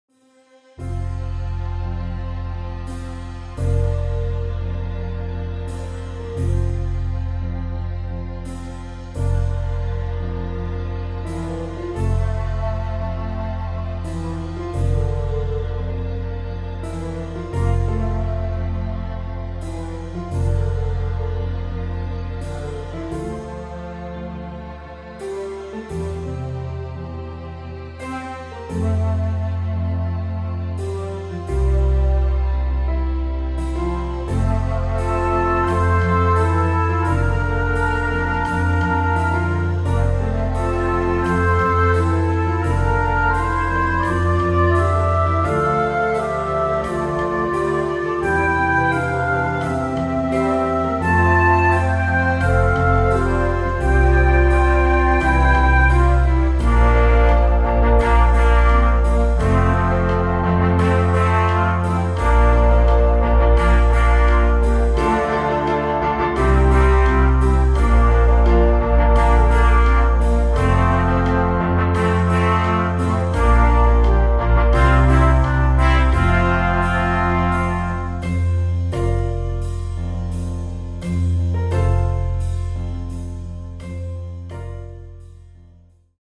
Australian choral music
Genre : Anthem